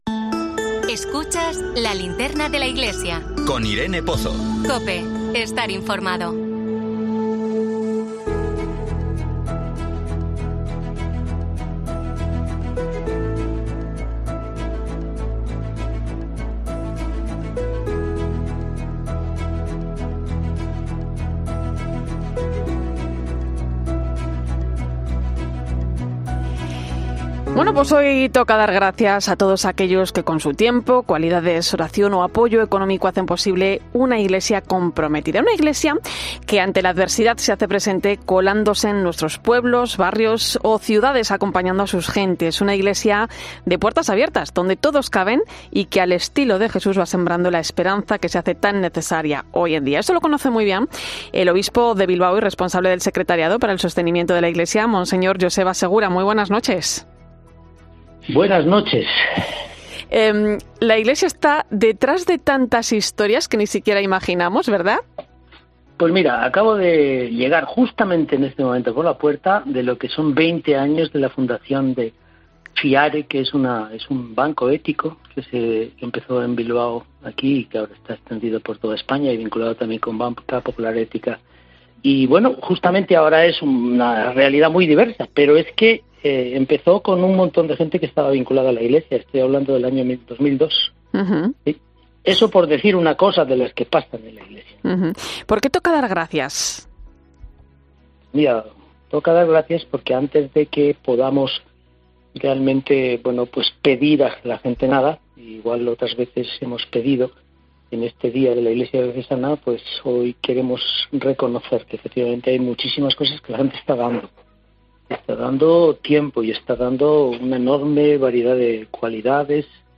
El responsable del Secretariado para el Sostenimiento de la Iglesia ha estado en COPE por el Día de la Iglesia Diocesana para dar las gracias a los...